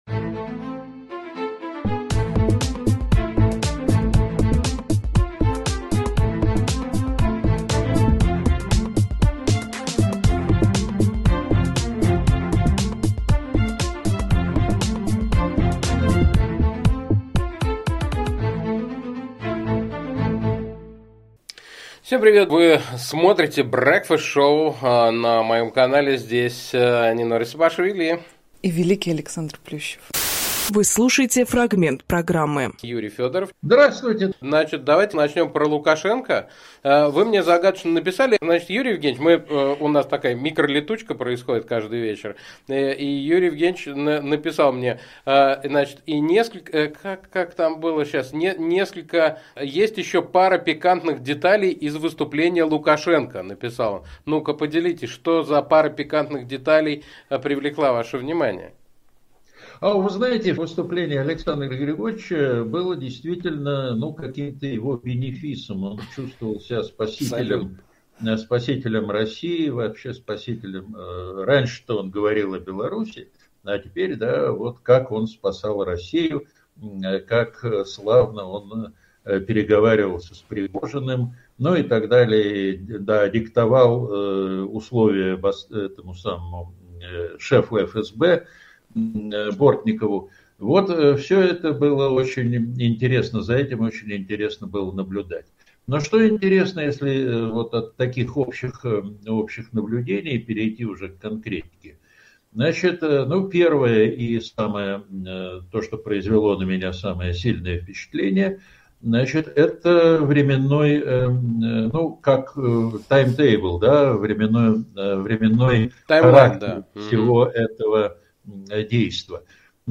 Фрагмент эфира Breakfast show.